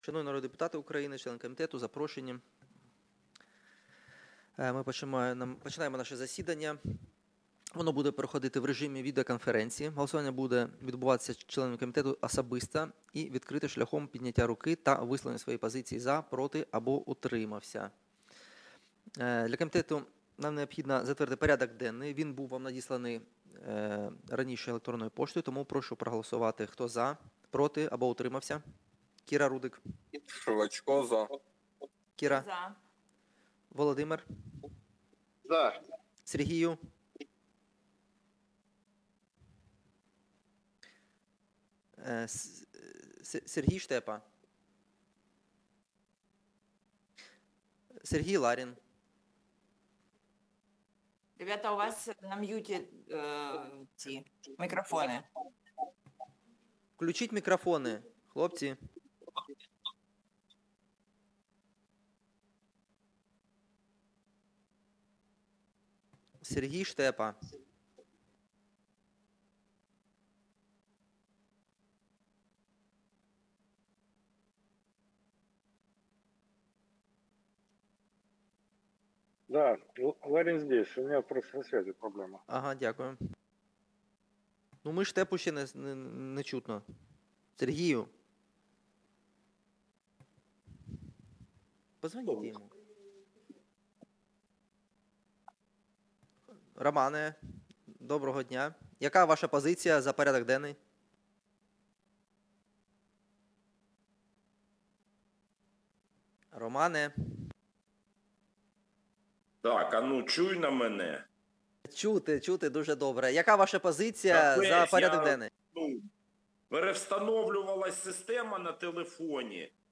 Аудіозапис засідання Комітету від 09.09.2025